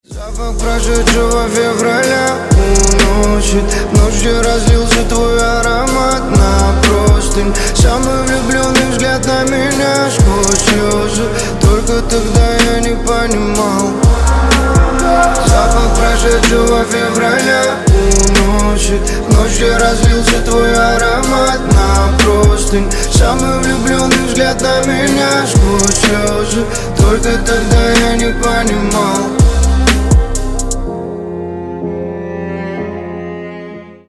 Грустные Рингтоны
Рэп Хип-Хоп Рингтоны